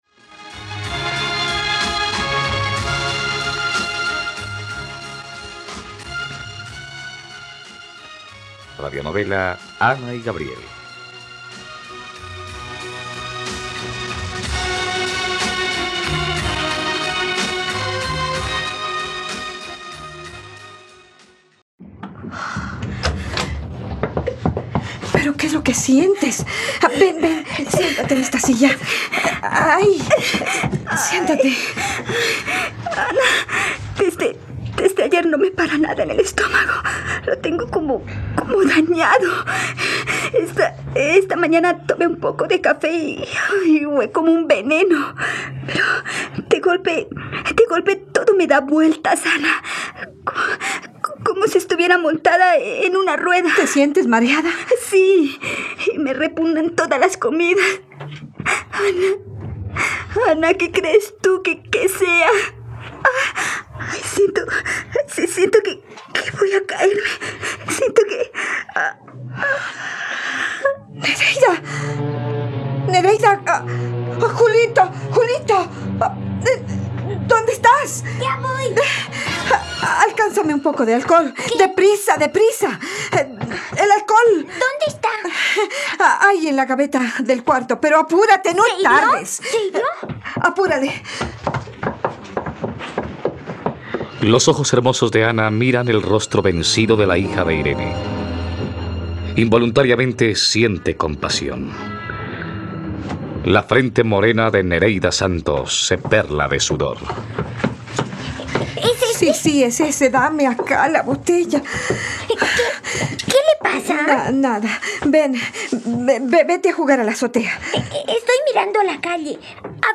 Ana y Gabriel - Radionovela, capítulo 49 | RTVCPlay